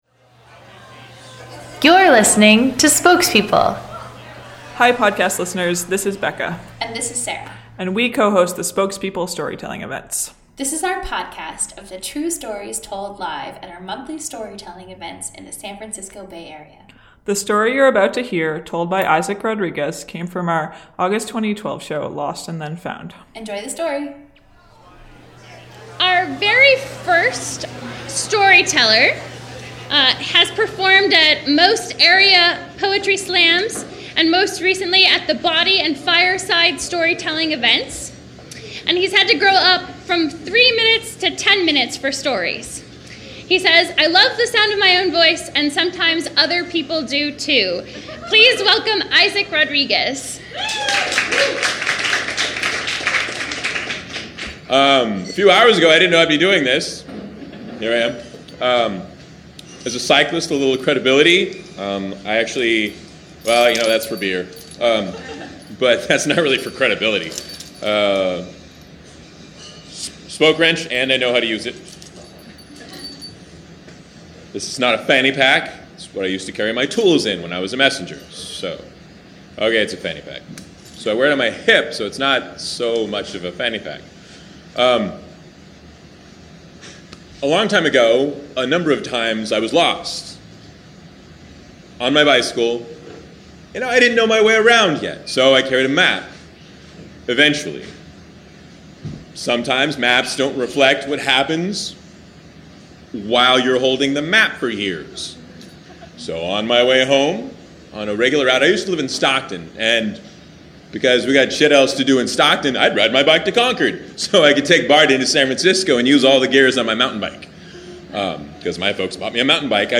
Homepage / Podcast / Storytelling
For fun and profit, this spoke wrench carrier sought after hillier, more unexpected terrain in the Bay Area. This story of life off the map came from our August 2012 show, “Lost and Then Found.”